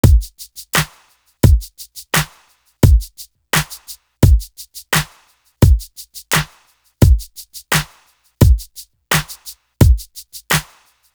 Desire Drum.wav